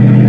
engine6.wav